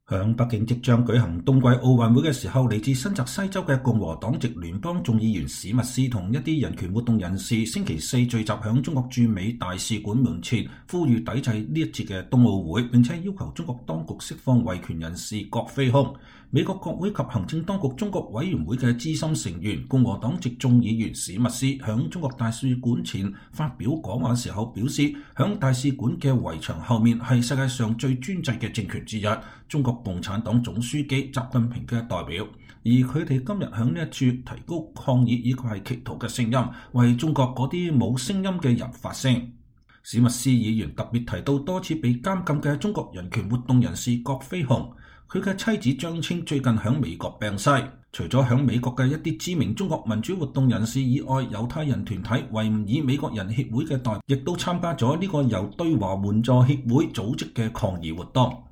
美議員和人權人士在中國大使館前抗議